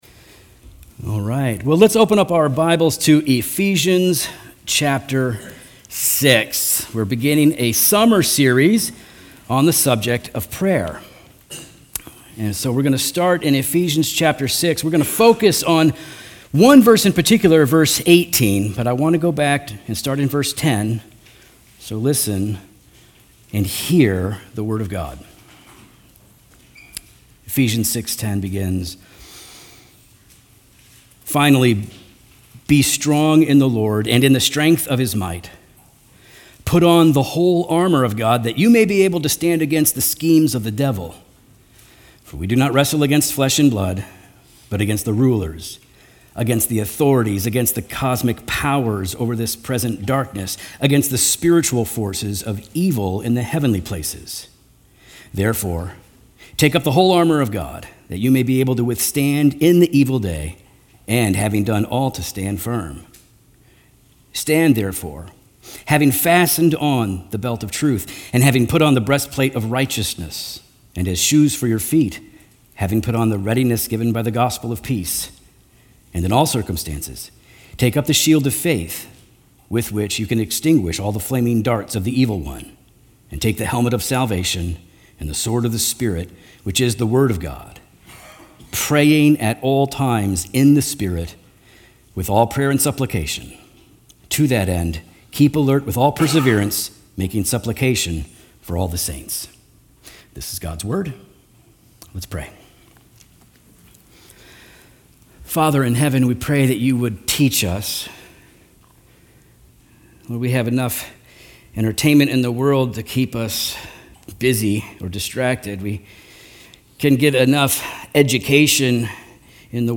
Redeemer Fellowship Sermons Podcast - What is Prayer? | Free Listening on Podbean App